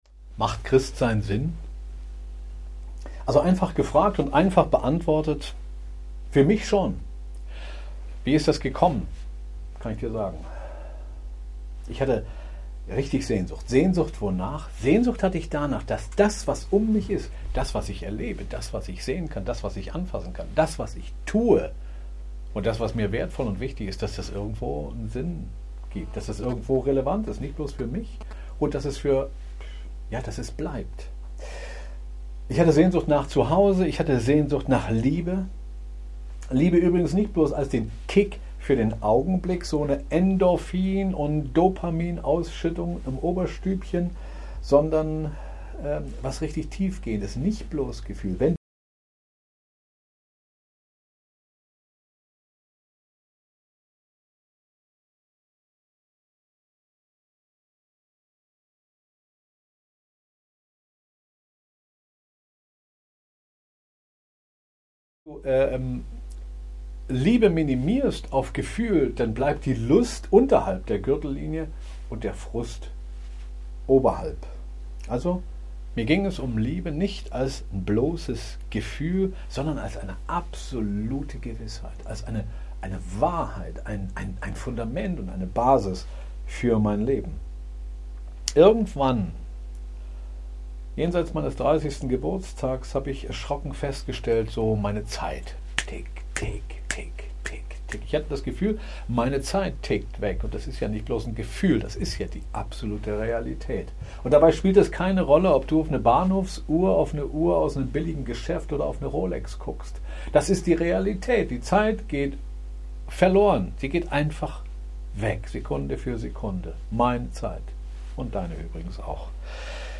Warum Christ sein? (MP3-Audio-Predigt)